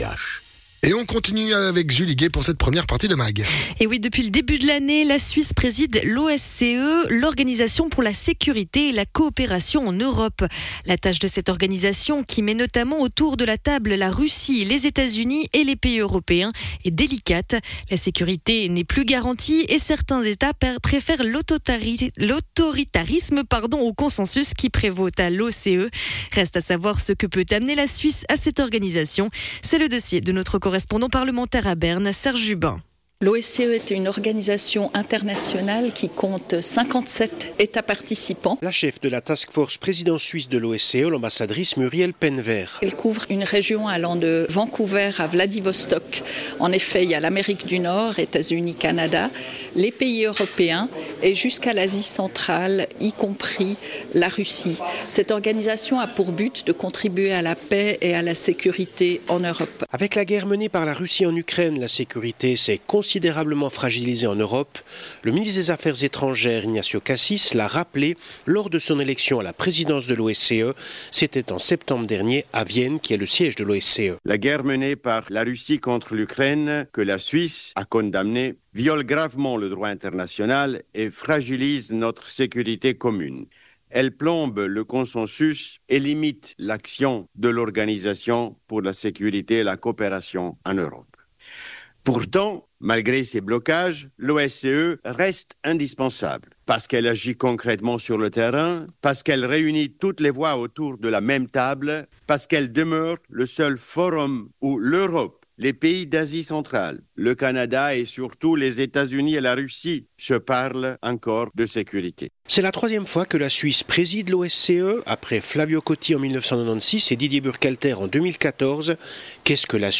Dossier de nos correspondants à Berne: la Suisse préside en 2026 l’OSCE, l’Organisation pour la sécurité et la coopération en Europe